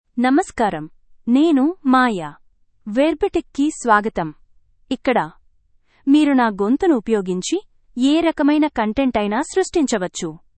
FemaleTelugu (India)
Maya — Female Telugu AI voice
Voice sample
Listen to Maya's female Telugu voice.
Maya delivers clear pronunciation with authentic India Telugu intonation, making your content sound professionally produced.